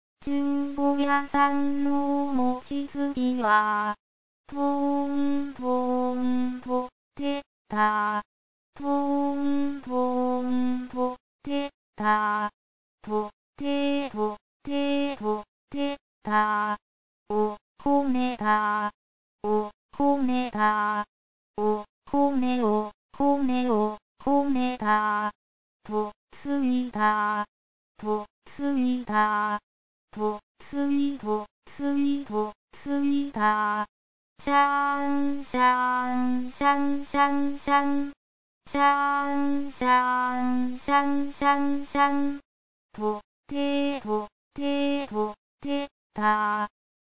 作詞作曲；日本のわらべうた
アカペラを聴く